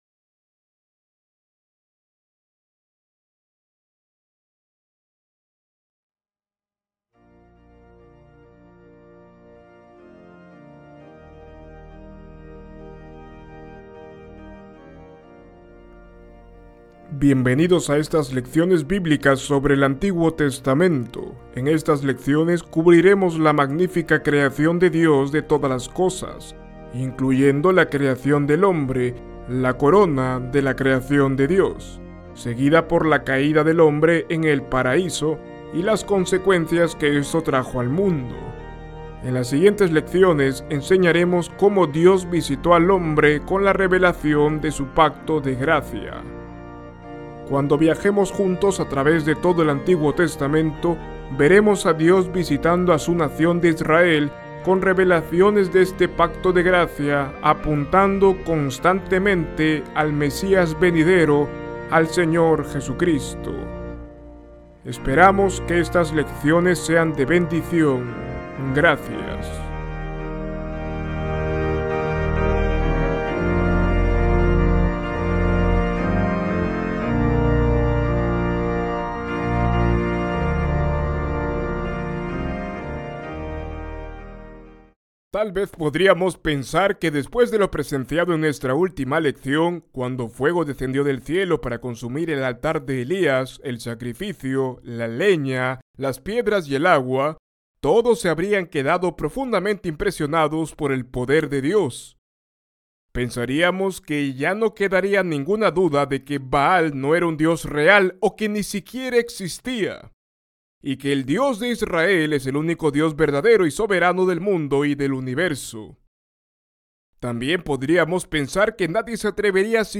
Esta lección incluye las historias de los malvados hechos de Acab y Jezabel, y cómo Dios puso un justo fin a Acab, y cómo, al mismo tiempo, protegió a Josafat, el rey piadoso de Judá. Ver video Descargar video MP4 Escuchar lección Descargar audio en mp3 Ver transcripción en PDF Descargar transcripción en PDF Guia de Estudio